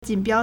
锦标 (錦標) jǐnbiāo
jin3biao1.mp3